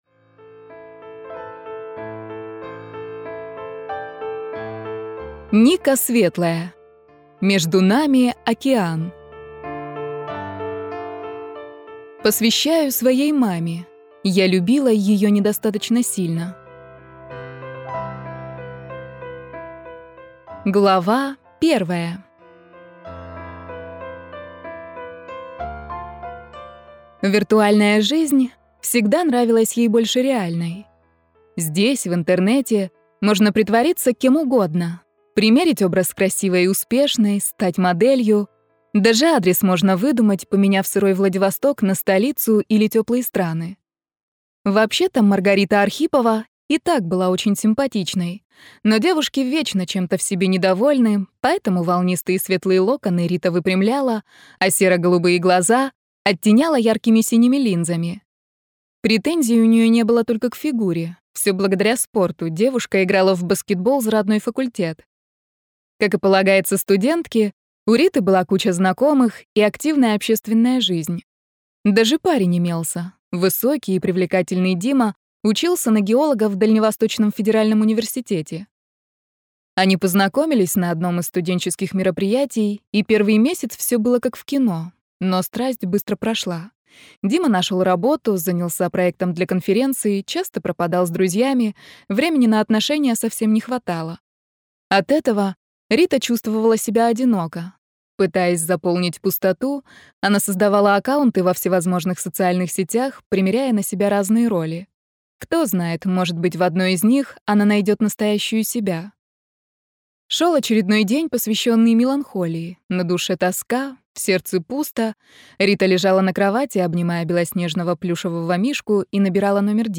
Аудиокнига Между нами океан | Библиотека аудиокниг